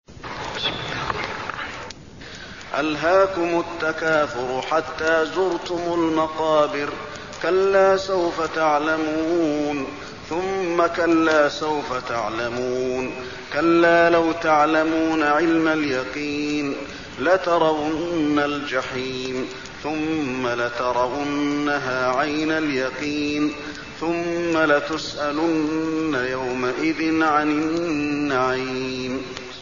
المكان: المسجد النبوي التكاثر